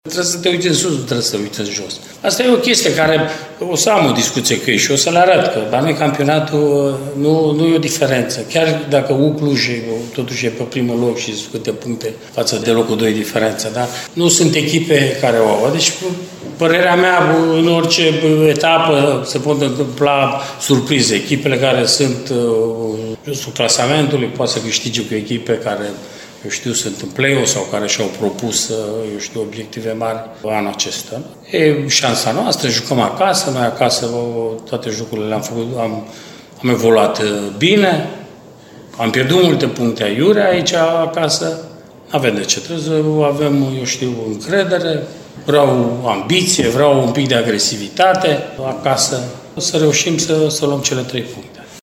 ”Principalul” arădenilor, Mircea Rednic, a fost provocat să comenteze și strângerea distanțelor dintre echipele aflate în partea inferioară a clasamentului: